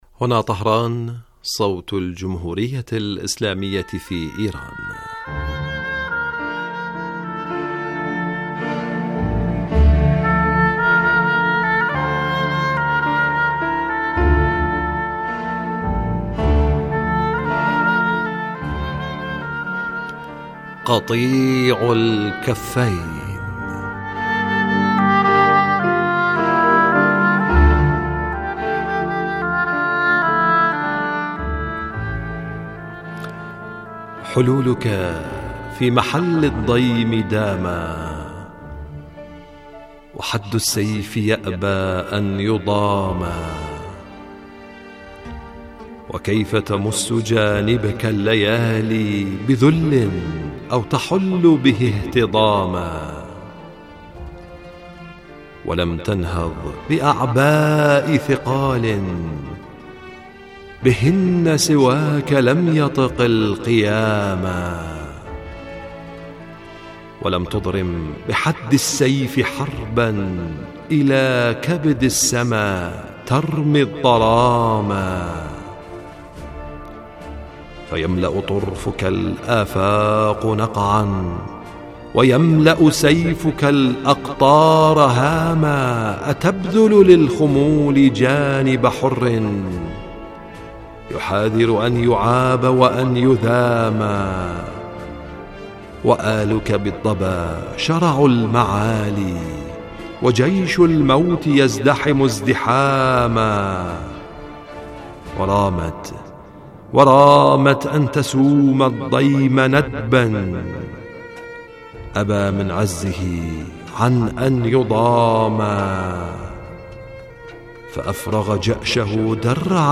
إذاعة طهران-استشهاد أبي الفضل العباس: برنامج خاص بمناسبة ذكرى شهادة العباس بن علي عليهما السلام